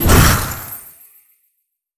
ice_blast_projectile_spell_03.wav